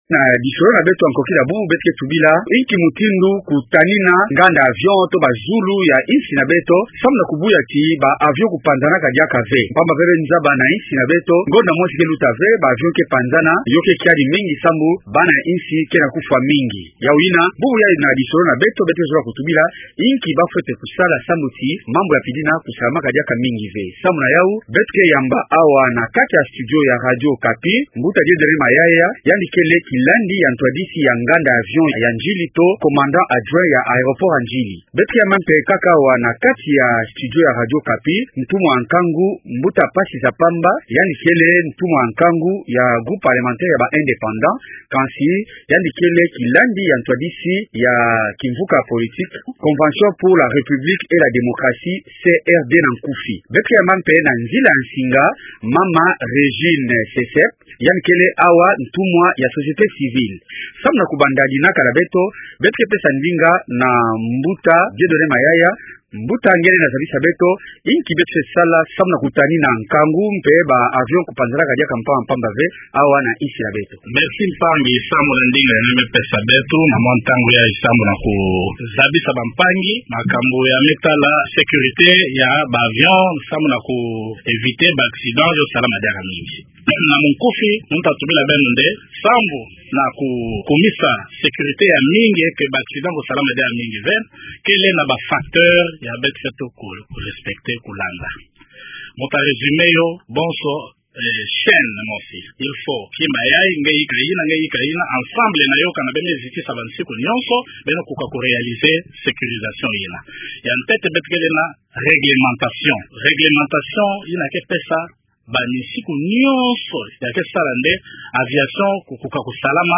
Inki ya fwana kusala samu na kutani zulu to bavion ya Congo Démocratique, samu na kubuya ti bisumbula kusalamaka ve konso ntangu ? Tala intu ya dilongi ya kinzonzi na beto ya nkokila ya bubu.
ntumuwa ya nkangu, mosi ya groupe parlementaire ya baIndépendants